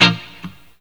RIFFGTR 18-L.wav